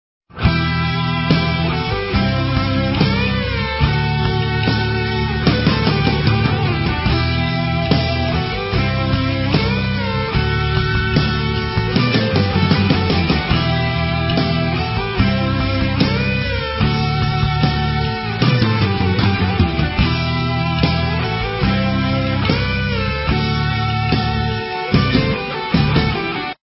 Ещё один шедевр мировой рок - музыки .